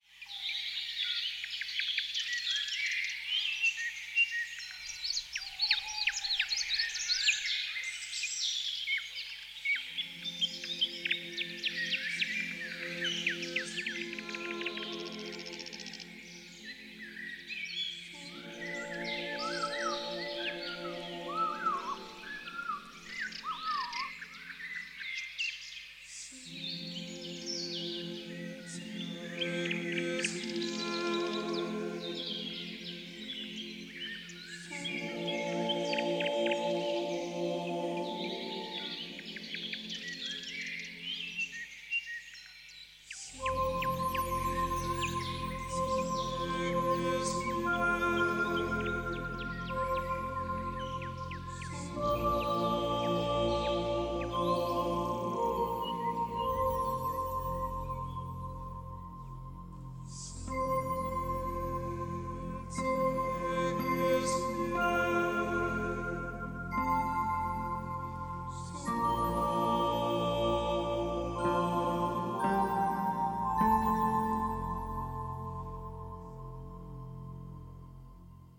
varied, nice melodies and sounds from nature
nice melodies, choir of birds and liturgic singing